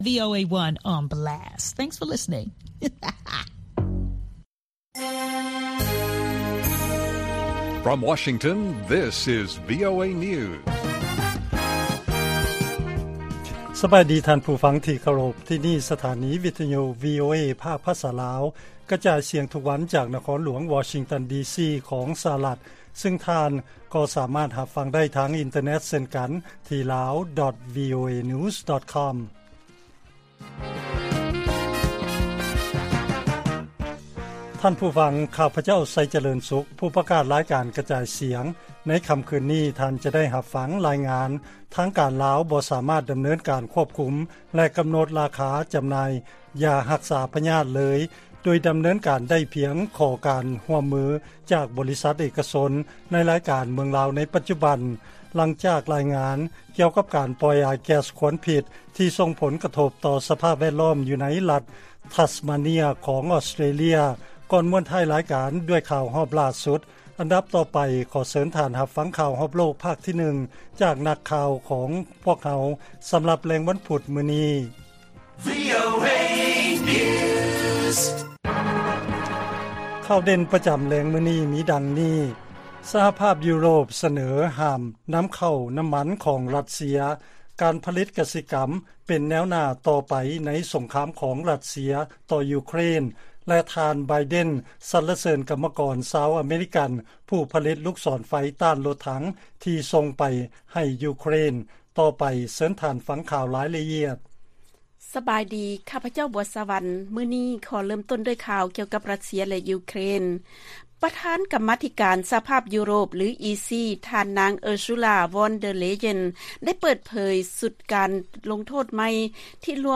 ລາຍການກະຈາຍສຽງຂອງວີໂອເອ ລາວ: ສະຫະພາບຢູໂຣບ ສະເໜີໃຫ້ຫ້າມນຳເຂົ້ານ້ຳມັນ ຈາກຣັດເຊຍ ແລະຕັດທະນາຄານຣັດເຊຍ ຈາກລະບົບໂອນເງິນສາກົນ